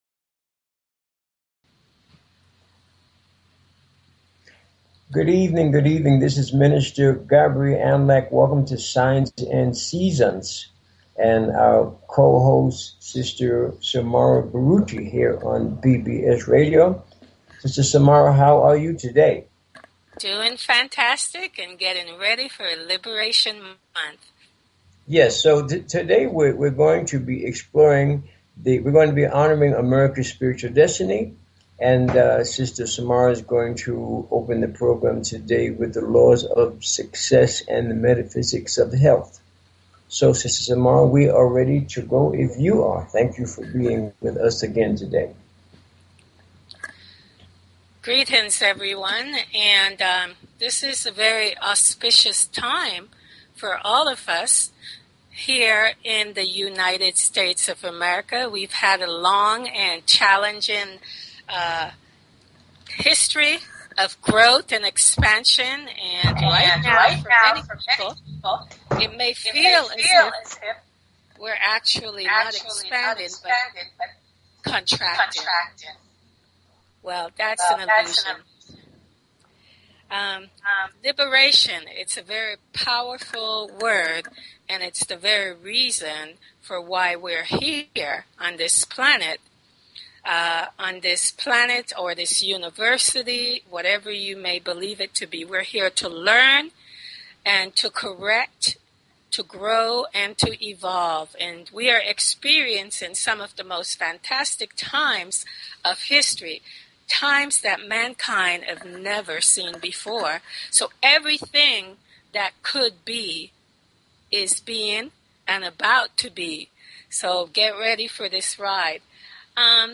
Talk Show Episode, Audio Podcast, Signs_and_Seasons and Courtesy of BBS Radio on , show guests , about , categorized as
And to demonstrate the practical value of Astrology and Numerology by giving on air callers FREE mini readings of their personal Astro-numerica energy profiles.